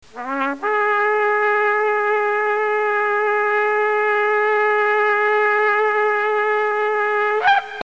The Tekieh is a single blast, rising at the end.